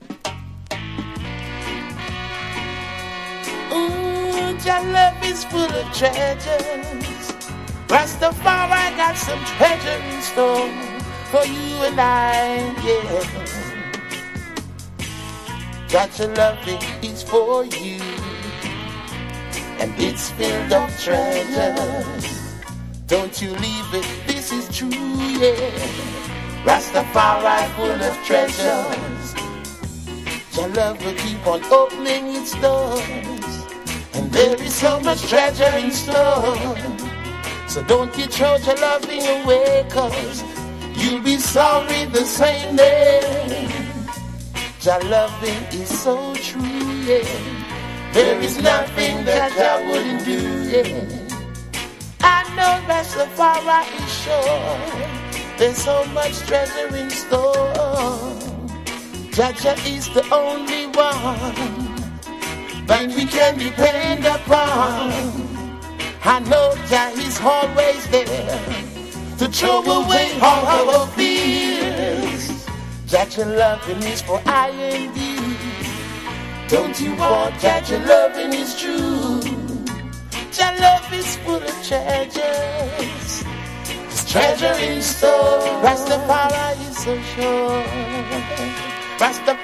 • REGGAE-SKA
DUB / UK DUB / NEW ROOTS